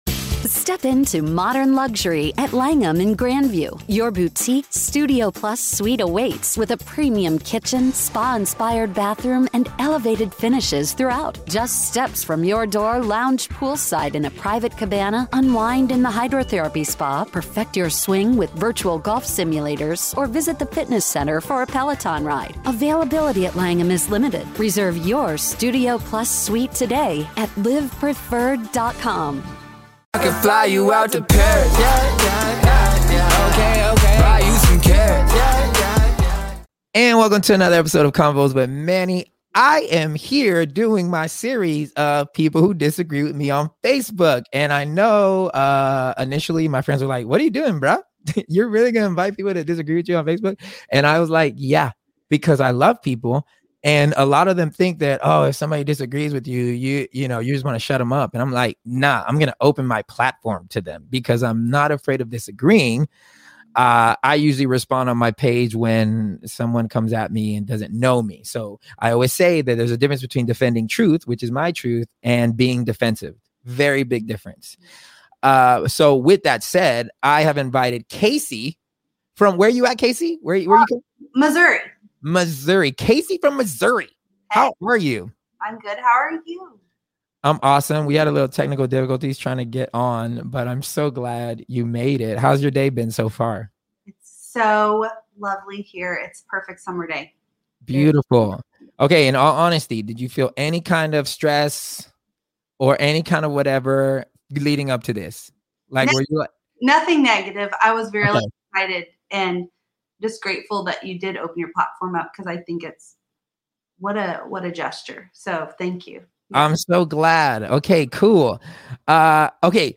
Addressing Disagreements on Facebook Pt 2- A Convo